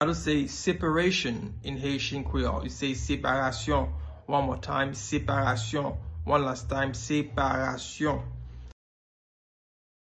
Pronunciation:
26.How-to-say-Separation-in-Haitian-Creole-–-Separasyon-pronunciation.mp3